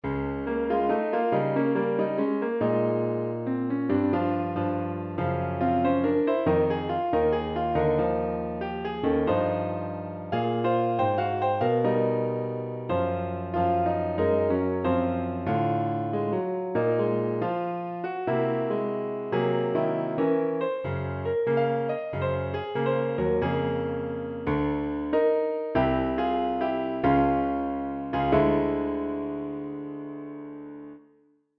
If we think of the piece as beginning in Bb minor shifting to F minor and then to Ab, the basic harmony is pretty straight forward (Example 1).